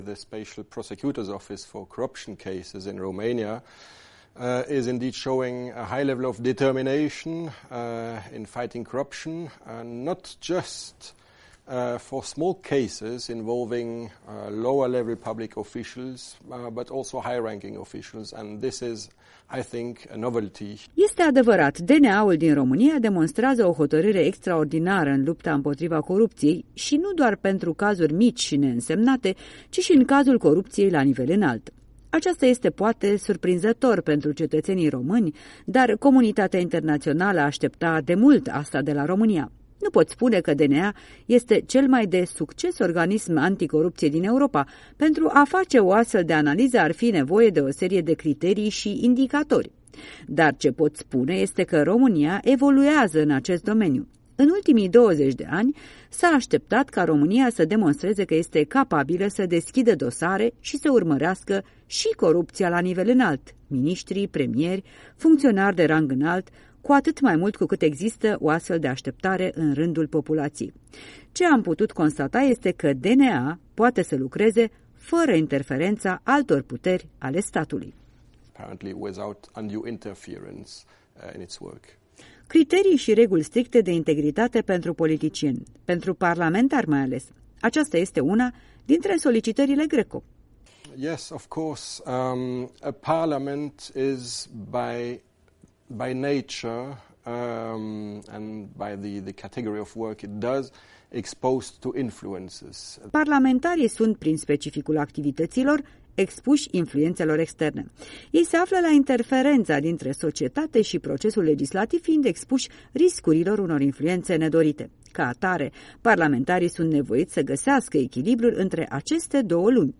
Interviu cu unul din experții Grupului Statelor împotriva Corupției (GRECO).